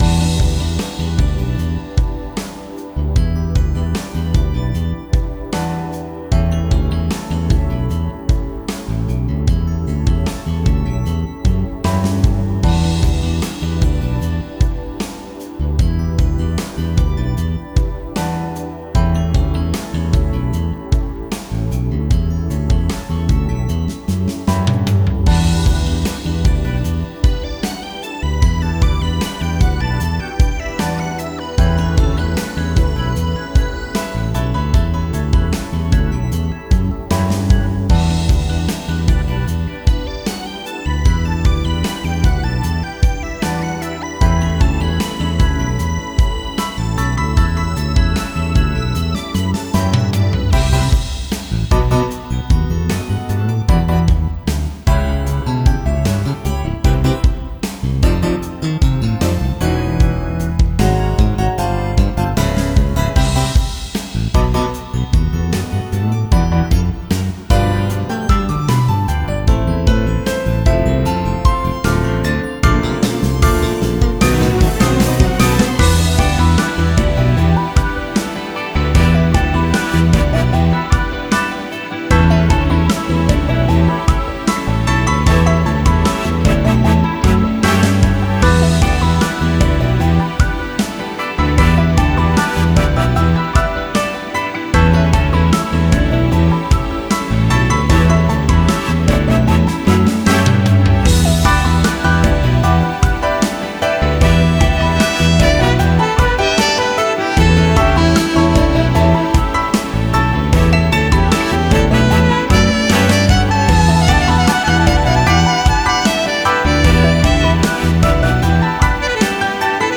Features nice jazzy music: